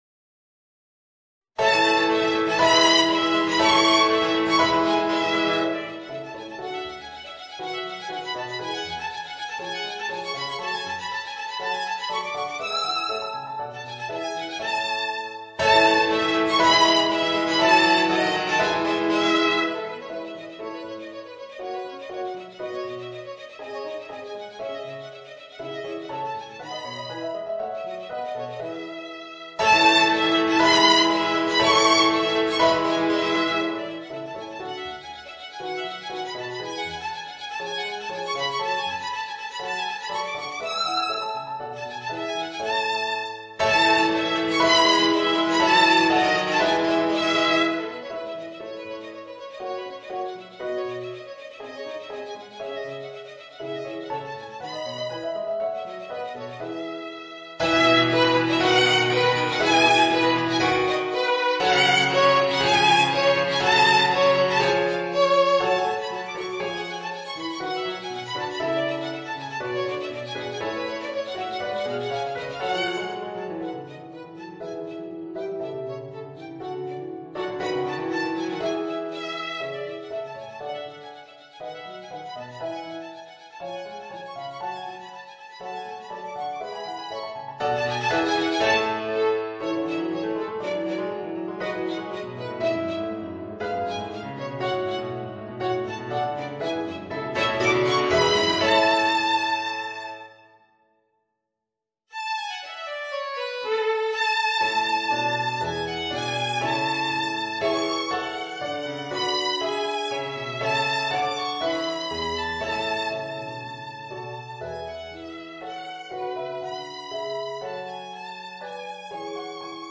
Voicing: Violin and Organ